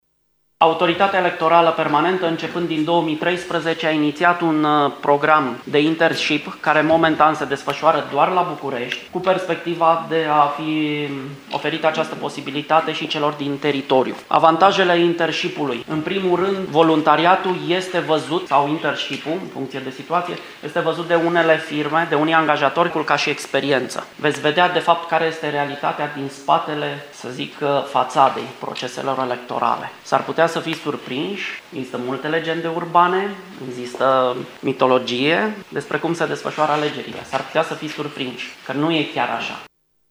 a explicat unui grup de elevi prezenţi la un workshop organizat la Universitatea Petru Maior că participarea la un astfel de program le poate arăta faţa nevăzută a unui scrutin: